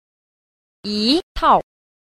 5. 一套 – yī tào – nhất sáo (một bộ)